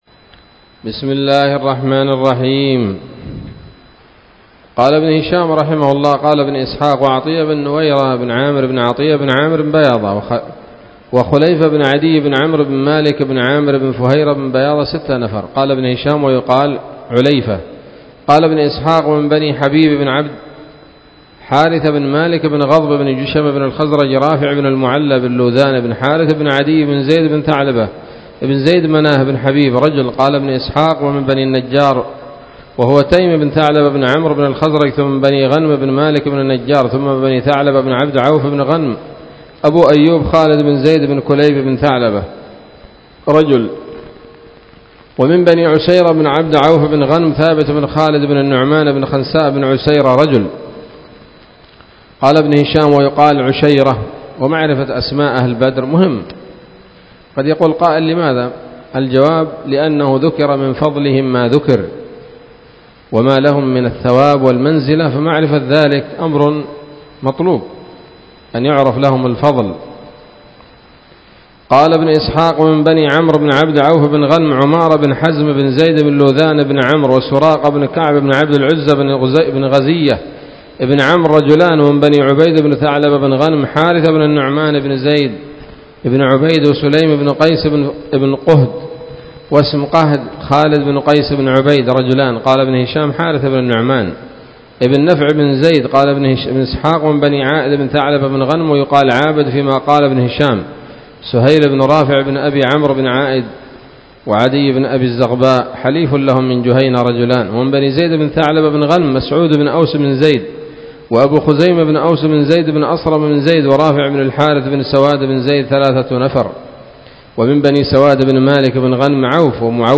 الدرس الثامن والثلاثون بعد المائة من التعليق على كتاب السيرة النبوية لابن هشام